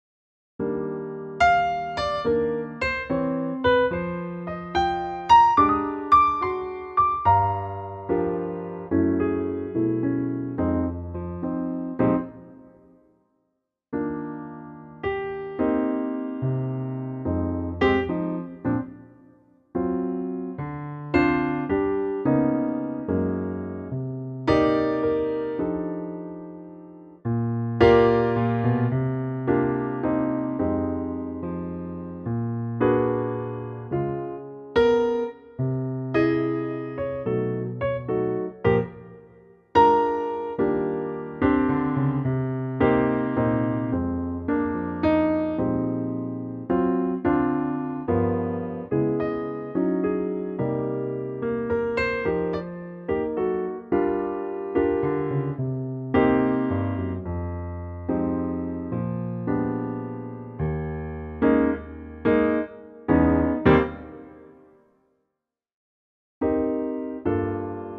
key Eb
key - Eb - vocal range - Eb to Ab